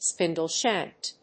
アクセントspíndle‐shànked